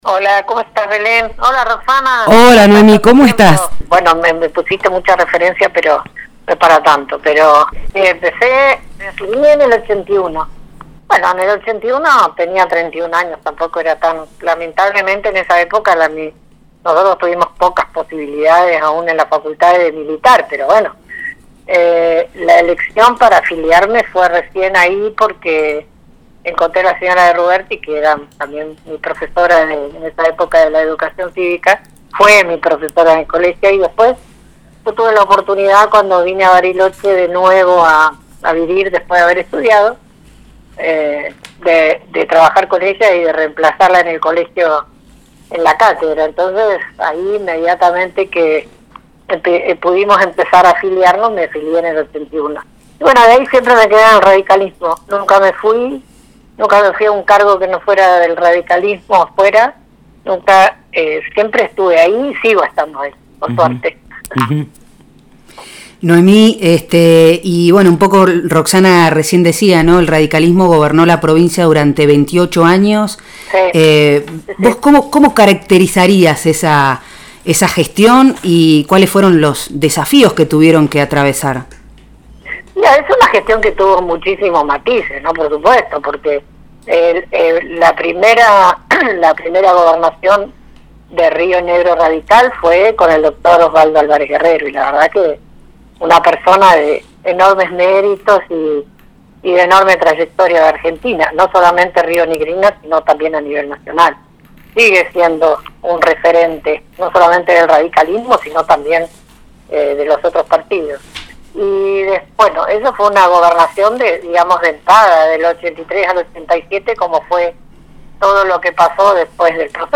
El primer capítulo de Hacete los rulos se propuso poner en escena voces de referentes de las fuerzas políticas tradicionales de Bariloche para transitar parte de la historia local y provincial. Por parte del radicalismo se sumó al aire Noemí Sosa, Senadora y Concejala mandatos cumplidos, ex funcionaria provincial y municipal.